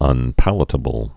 (ŭn-pălə-tə-bəl)